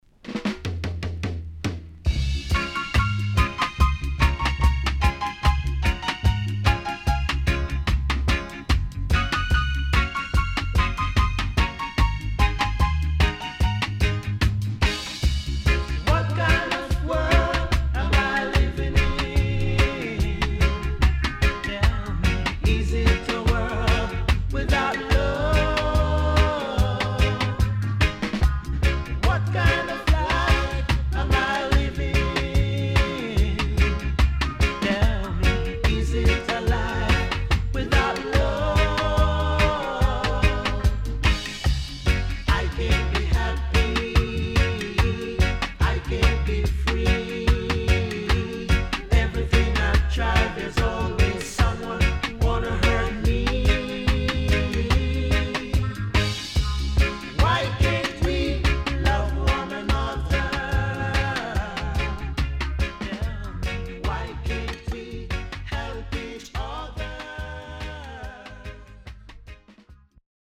HOME > Back Order [VINTAGE LP]  >  STEPPER